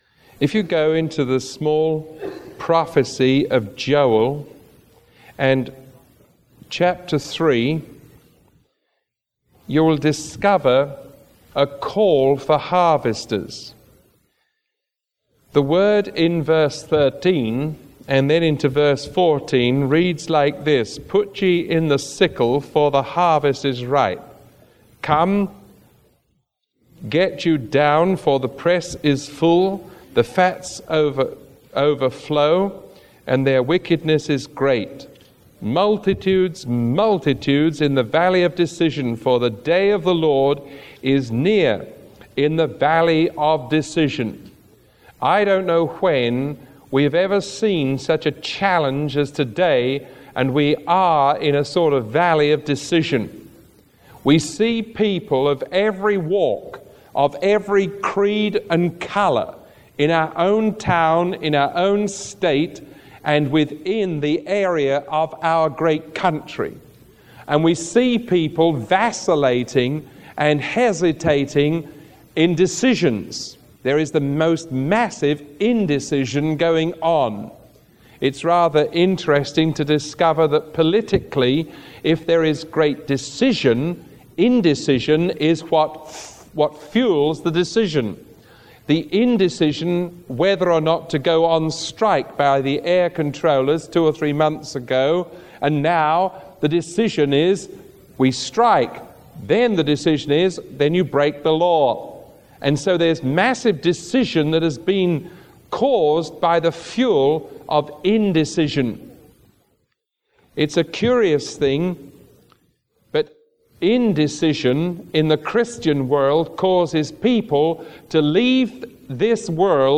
Sermon 0395A recorded on August 5, 1981 teaching from Joel – A Call for Harvesters.